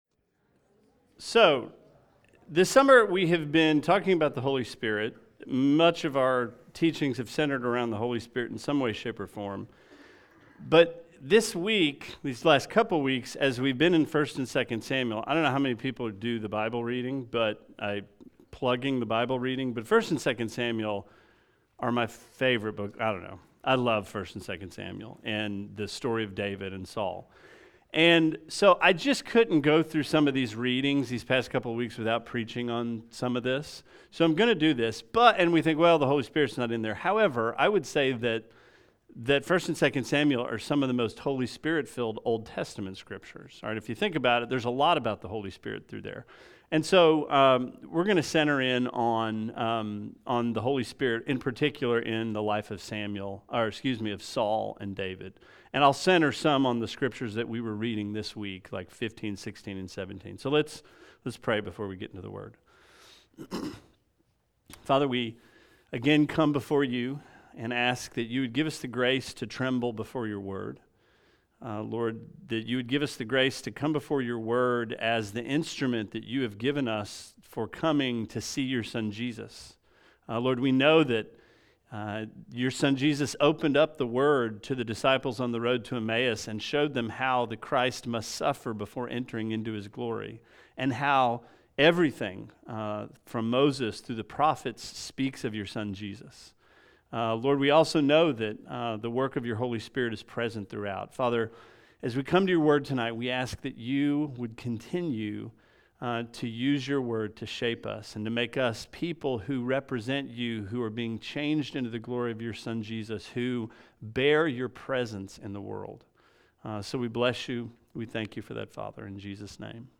Sermon 07/06: Holy Spirit in Saul and David